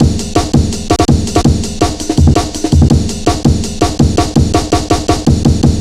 Index of /90_sSampleCDs/Zero-G - Total Drum Bass/Drumloops - 2/track 40 (165bpm)